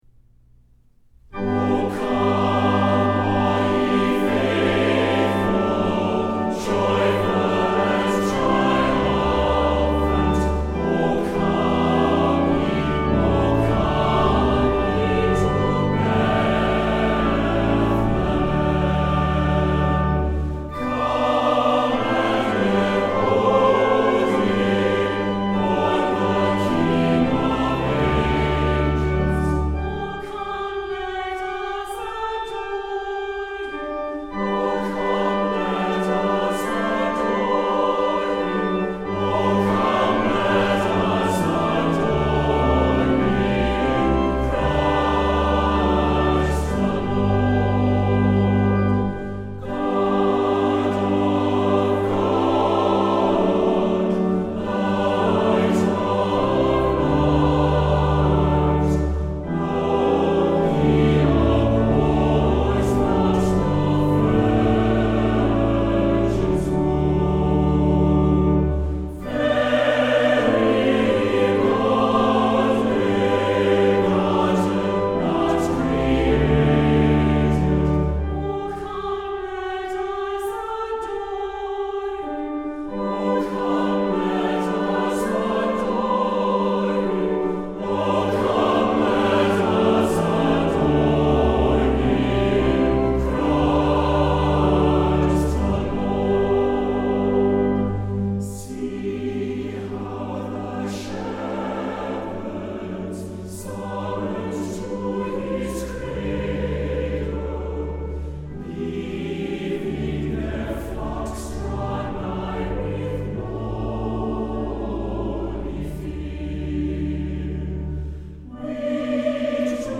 choral recording